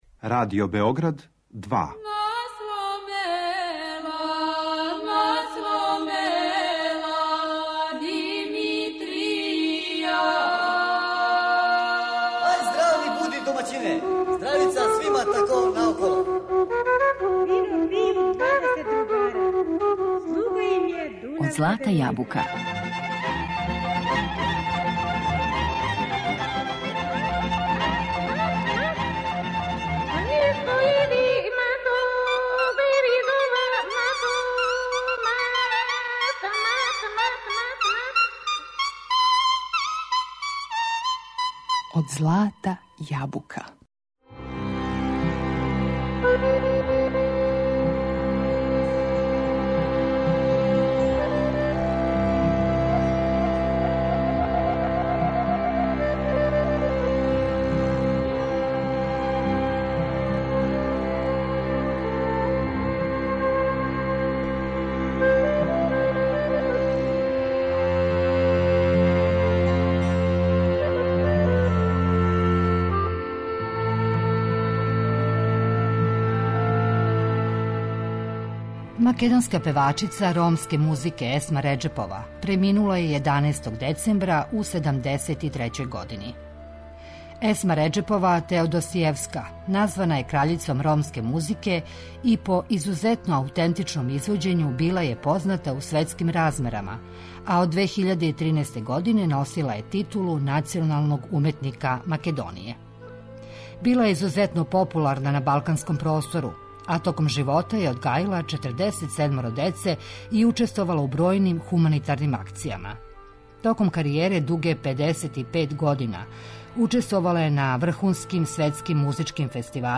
У две емисије, у знак сећања на ову уметницу, поред песама које су обележиле њену каријеру, слушамо и документарни материјал који смо забележили 2003. године.